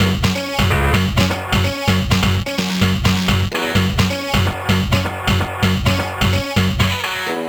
Nines2_128_F#_Dry.wav